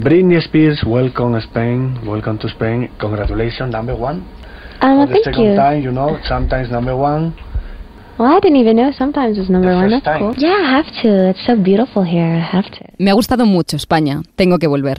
Entrevista a la cantant Britney Spears
FM